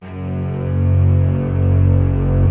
Index of /90_sSampleCDs/Optical Media International - Sonic Images Library/SI1_Swell String/SI1_Soft Swell